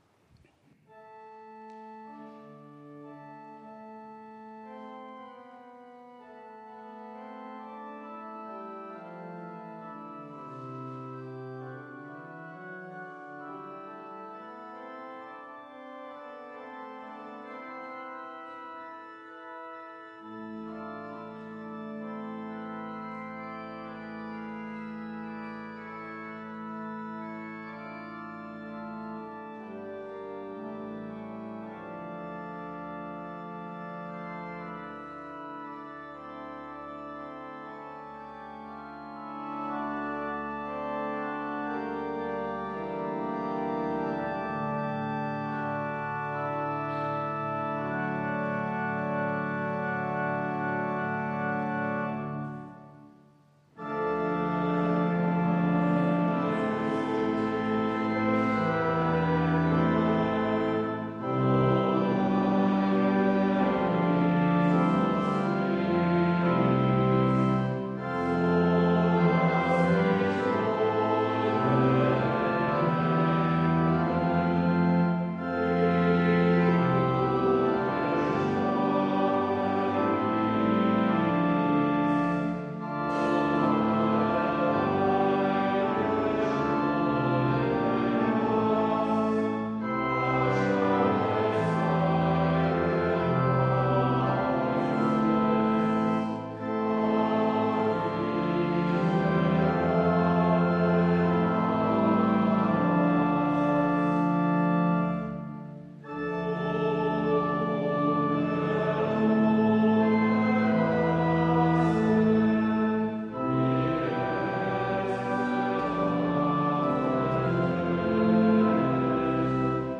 Audiomitschnitt unseres Gottesdienstes vom Sonntag Lätare 2024